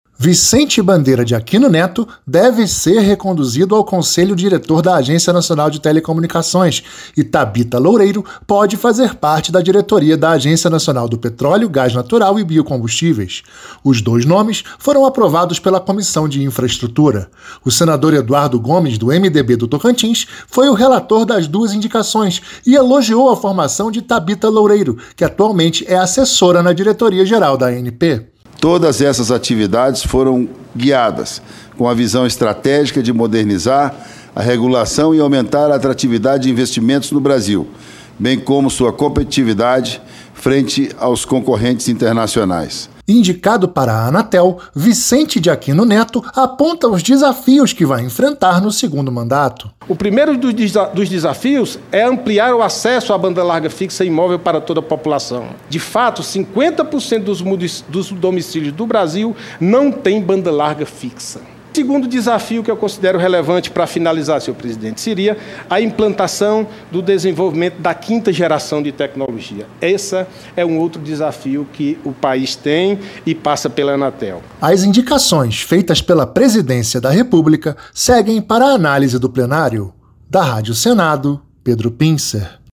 As duas indicações foram aprovadas nesta segunda-feira (14) pela Comissão de Infraestrutura e agora serão votados pelo Plenário. Ouça os detalhes na reportagem